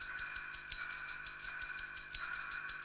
Drum Monkey 02.WAV